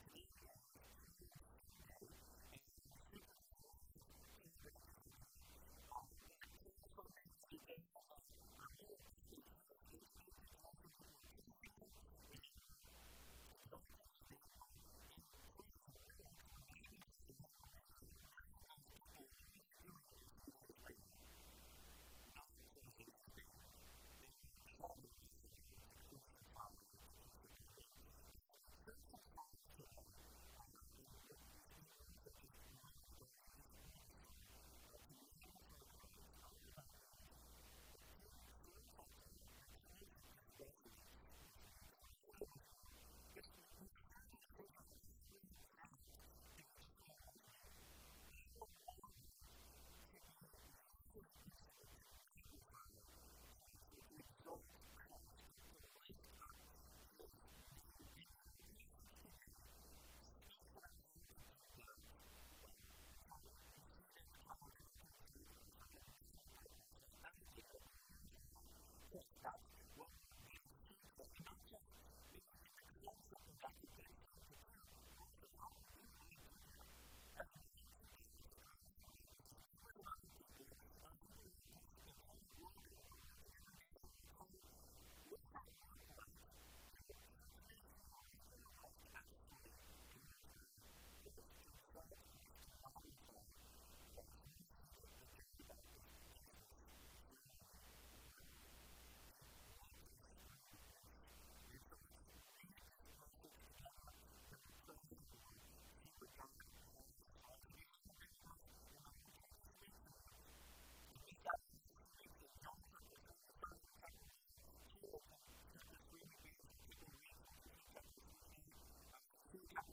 In this message we learn from the example of John the Baptist how to exalt Christ in our hearts and lives. Sermon